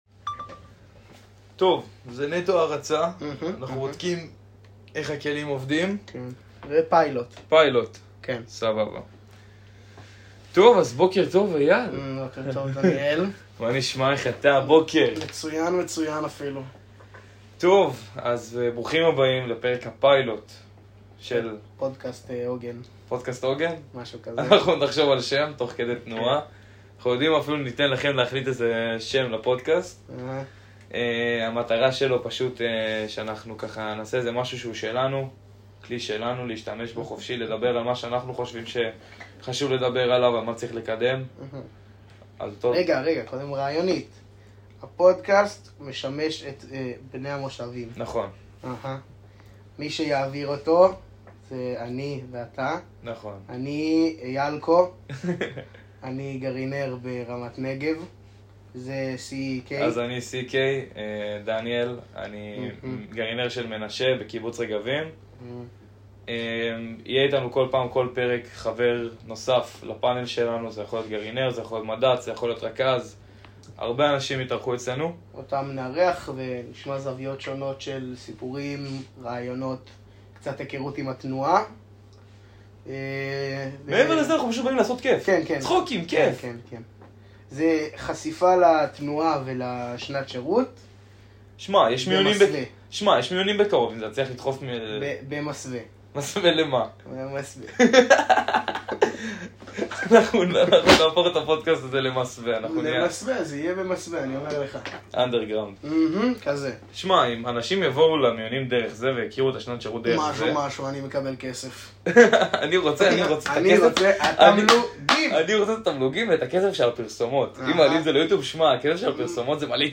תוכנית פודקאסט שבה נארח חברי גרעין , חניכים , מדריכים ואנשי צוות בשביל צחוקים דאחקות והרבה הומור